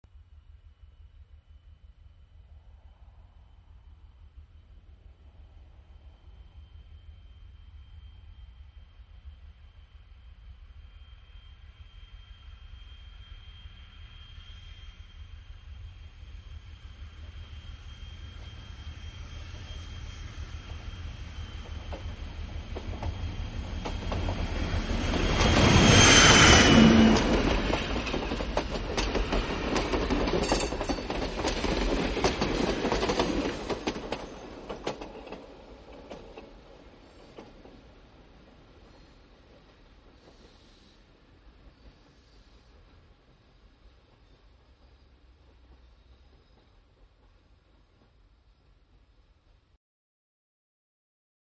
Diesel Train and 3 Cars By, Long In
Category: Sound FX   Right: Both Personal and Commercial